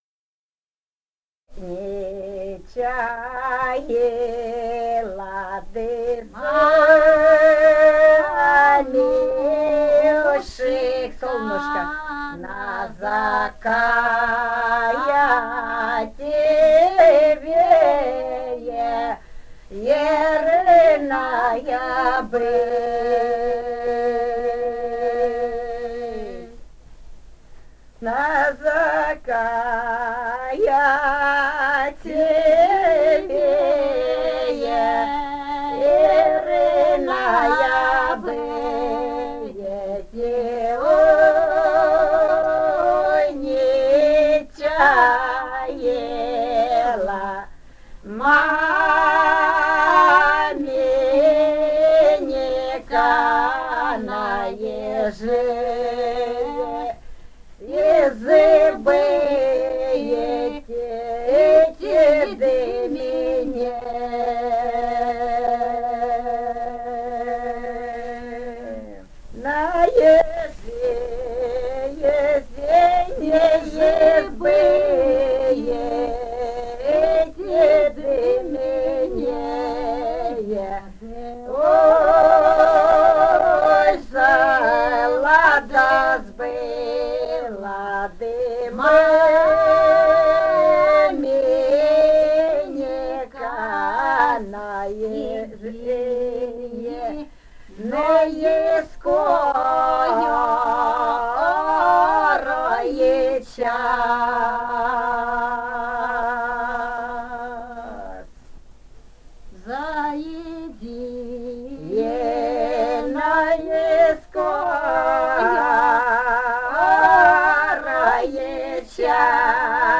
Этномузыкологические исследования и полевые материалы
«Не чаяло солнушко» (свадебная).
Румыния, с. Переправа, 1967 г. И0973-03а